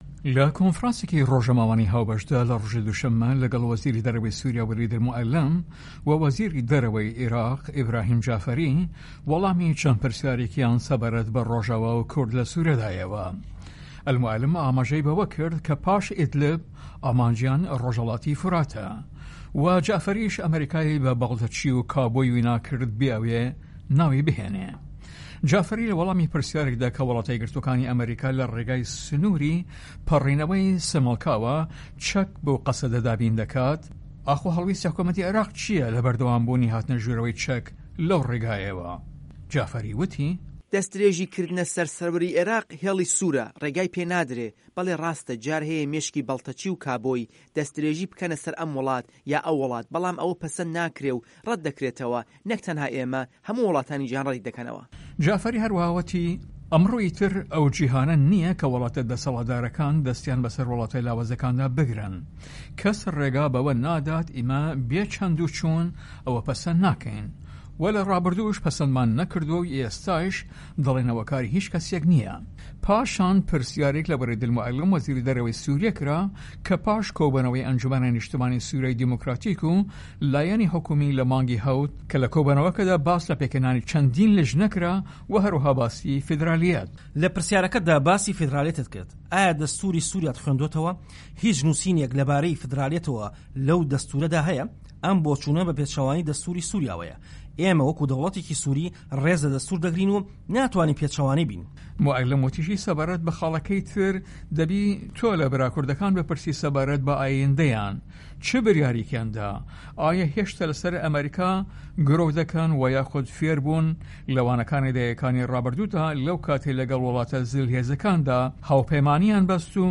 کۆنفرانسی رۆژنامەوانی وەزیرانی دەرەوەی سوریا و عێراق